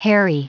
Prononciation du mot harry en anglais (fichier audio)
Prononciation du mot : harry